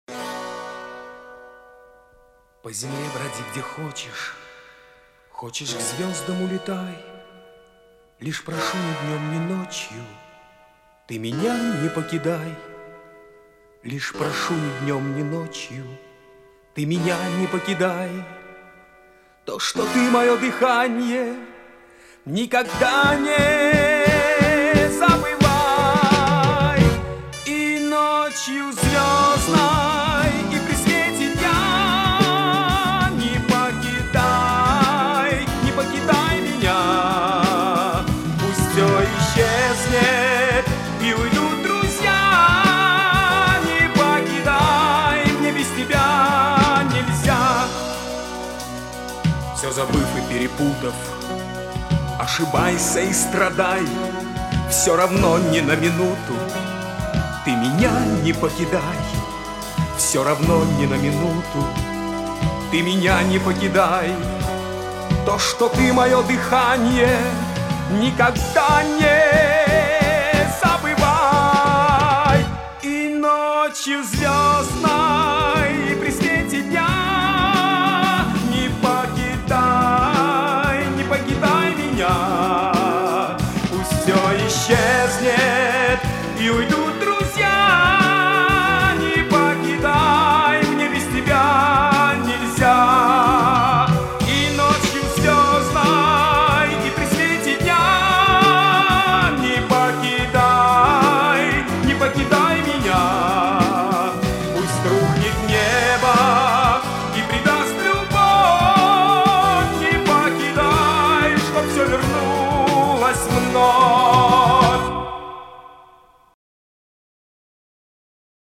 Вот Вам хорошее качество, которого ни у кого нет.....